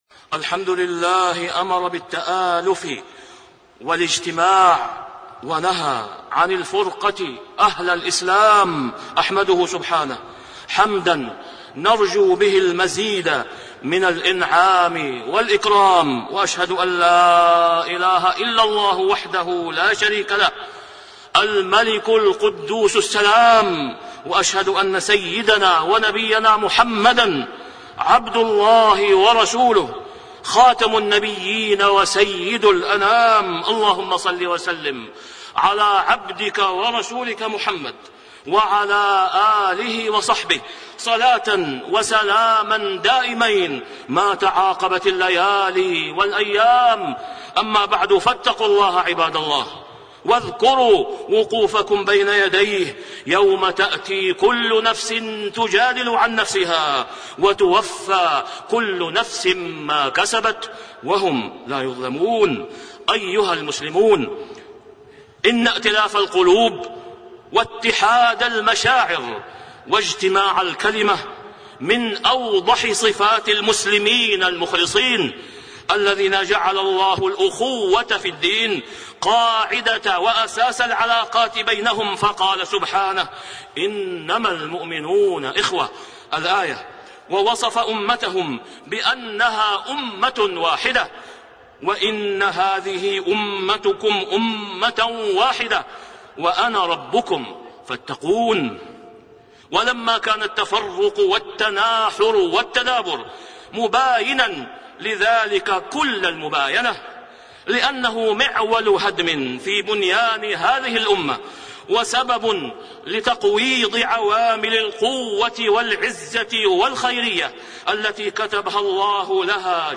تاريخ النشر ٩ شعبان ١٤٣٣ هـ المكان: المسجد الحرام الشيخ: فضيلة الشيخ د. أسامة بن عبدالله خياط فضيلة الشيخ د. أسامة بن عبدالله خياط خطورة الفرقة